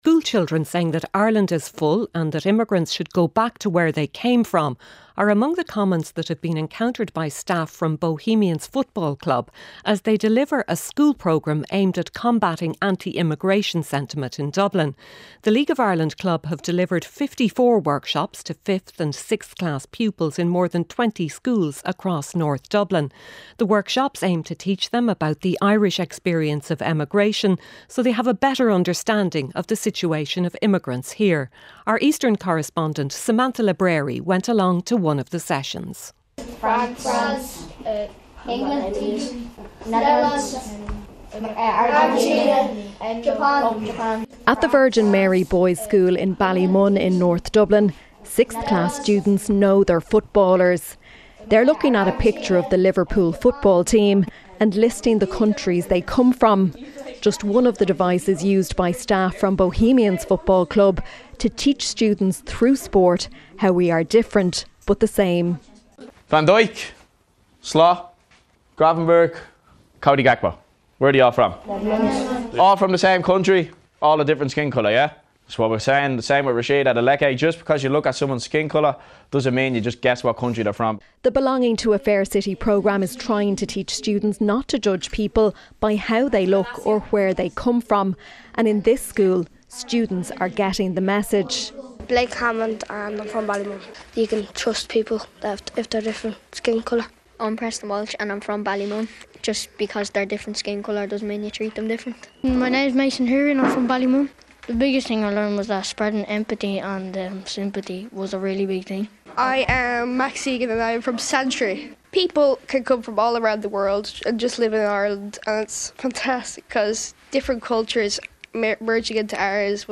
News, sport, business and interviews.